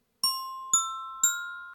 Guide des carillons
Gliding_hawk.ogg